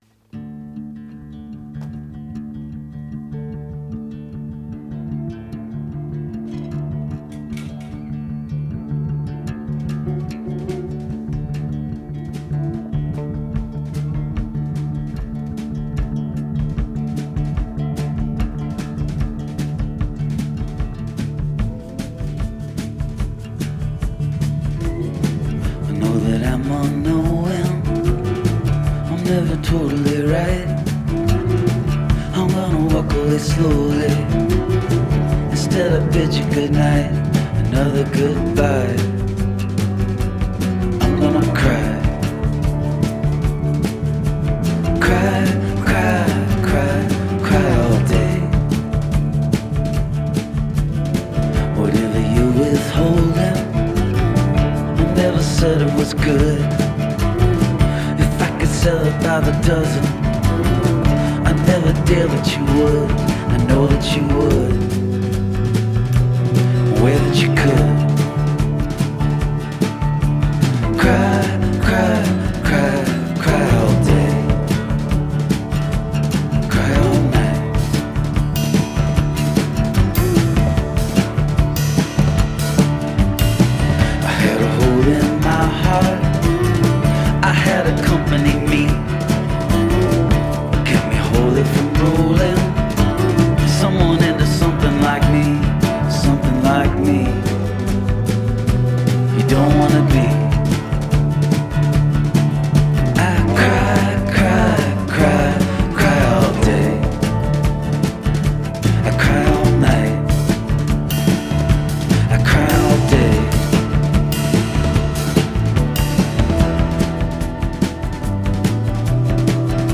the gently wonderful gallop of this awesome tune